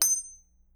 And pieces of metal.